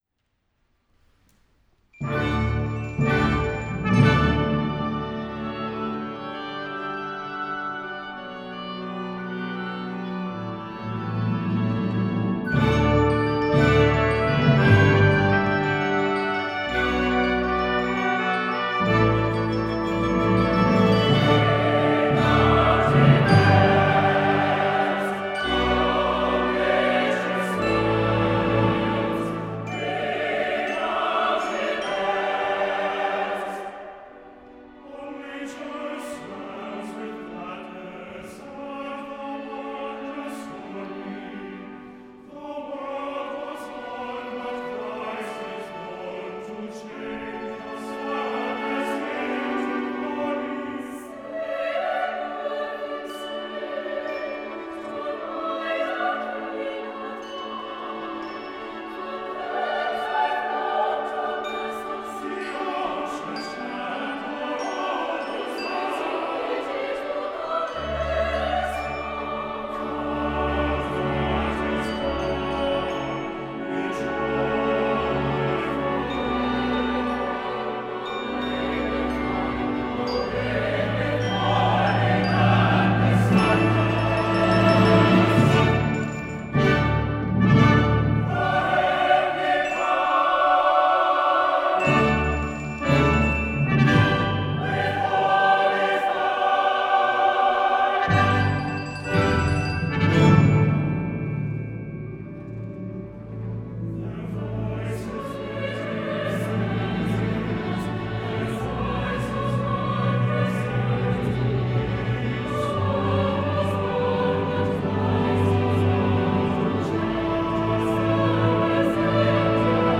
A majestic holiday anthem with chamber orchestra